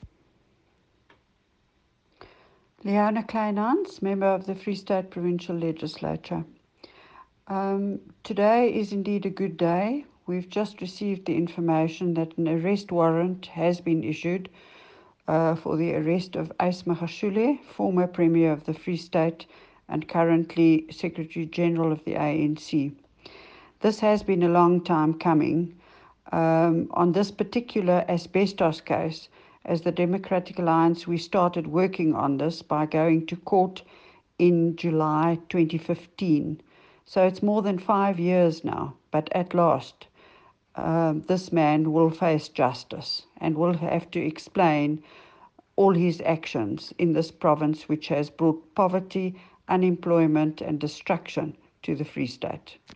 Note to Editors: Please find the attached soundbites in
Afrikaans by Leona Kleynhans MPL, Member of the Official Opposition in the Free State Legislature.